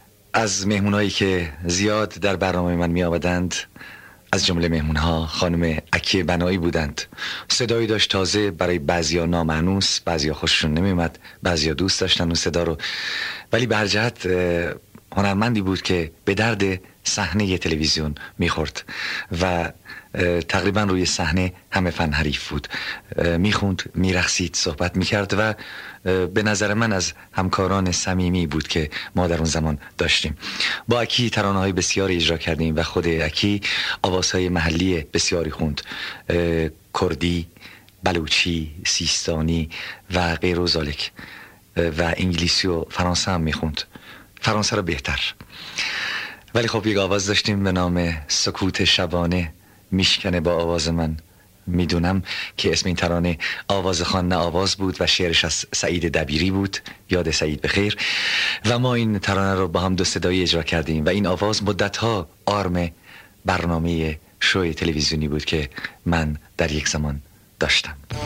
:سخن ترانه خوان